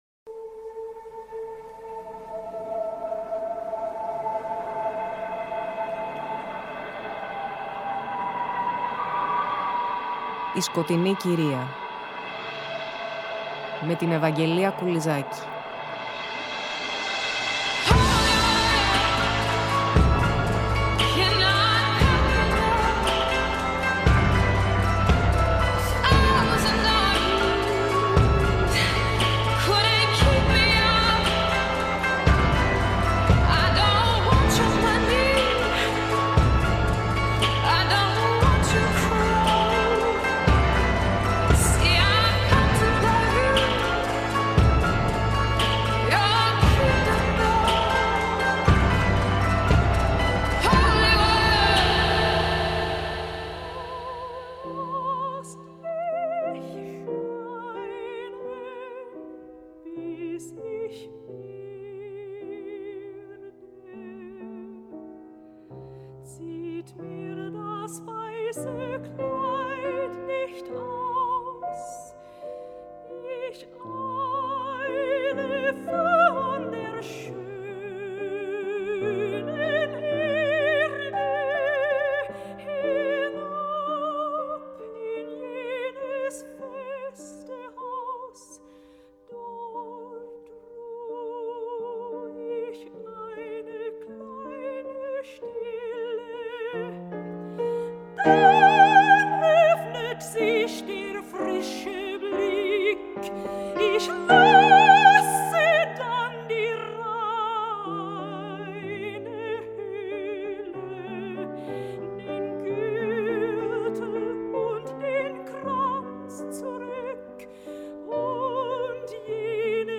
Η ΣΚΟΤΕΙΝΗ ΚΥΡΙΑ στο στ’ μέρος για την Clara Schumann, όπου παρακολουθούμε το ειδύλλιό της με τον Ρόμπερτ Σούμαν, ενάντια στις απαγορεύσεις του δεσποτικού πατέρα της, διαβάζοντας, όπως και στις προηγούμενες εκπομπές, αποσπάσματα από την αλληλογραφία τους.